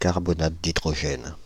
Ääntäminen
Synonyymit acide carbonique Ääntäminen France (Île-de-France): IPA: /kaʁ.bɔ.nat d‿i.dʁɔ.ɡɛn/ Haettu sana löytyi näillä lähdekielillä: ranska Käännöksiä ei löytynyt valitulle kohdekielelle.